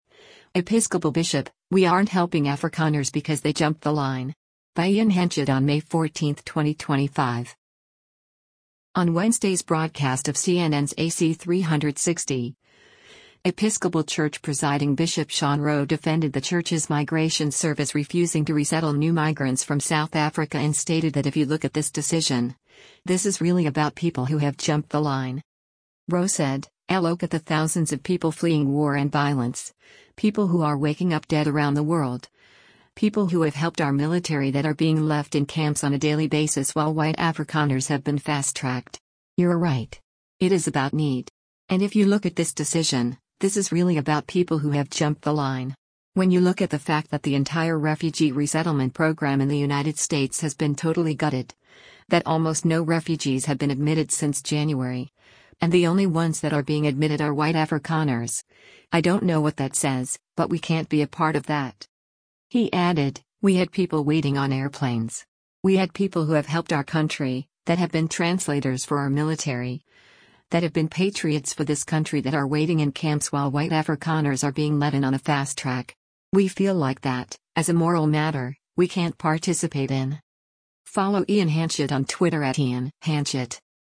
On Wednesday’s broadcast of CNN’s “AC360,” Episcopal Church Presiding Bishop Sean Rowe defended the church’s migration service refusing to resettle new migrants from South Africa and stated that “if you look at this decision, this is really about people who have jumped the line.”